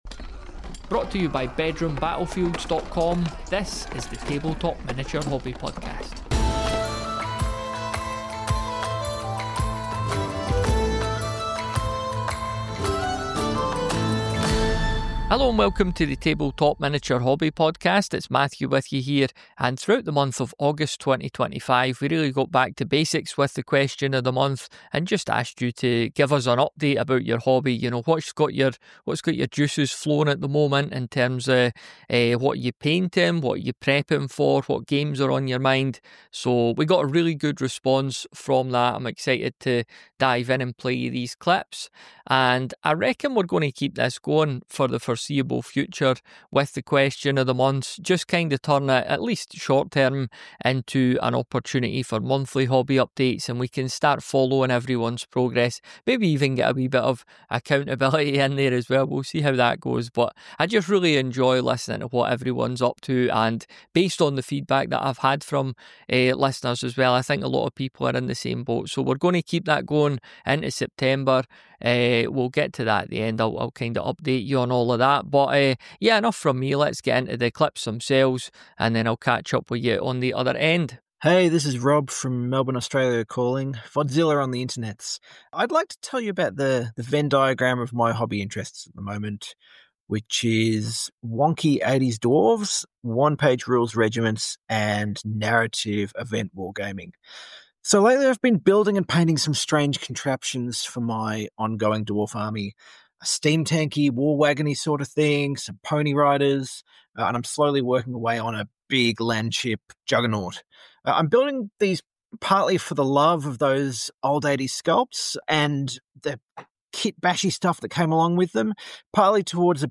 The Bedroom Battlefields Tabletop Miniature Hobby Podcast brings you conversations about collecting, gaming, painting, terrain, and much more - often with a nostalgic twist.